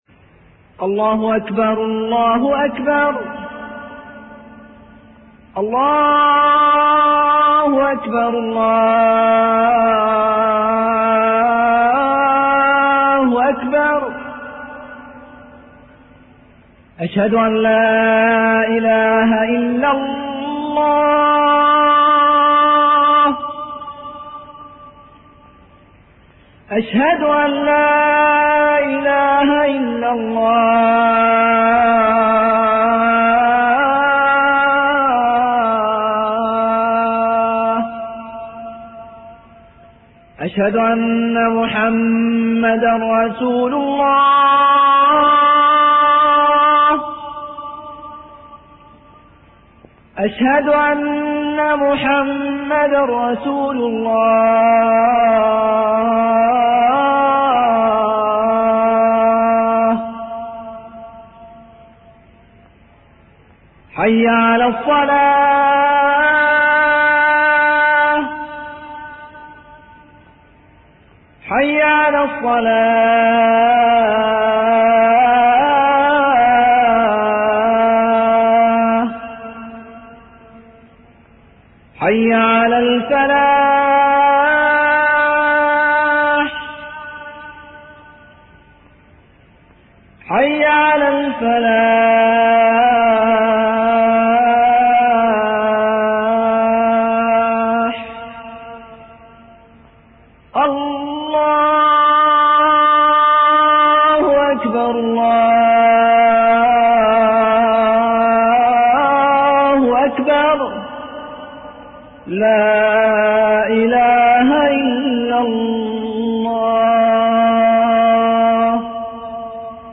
أناشيد ونغمات
عنوان المادة أذان-14